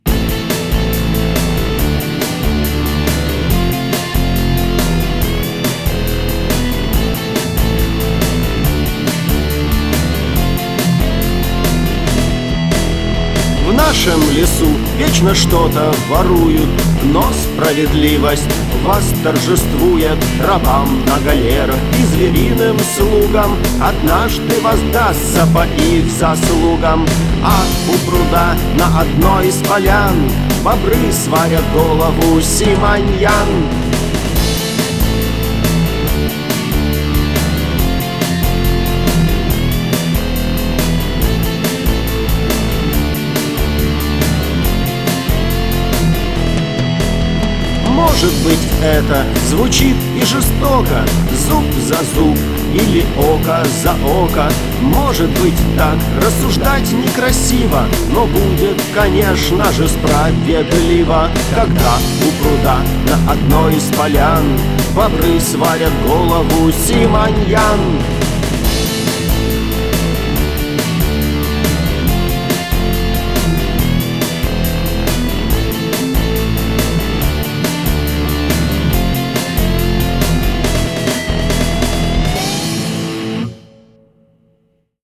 политический панк-рок